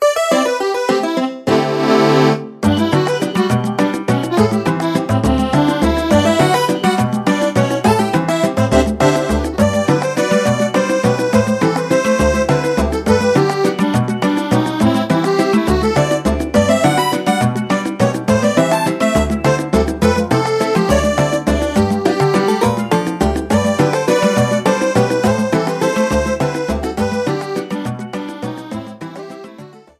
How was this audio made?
Ripped from the game's files. 30s long clip with 5s fadeout.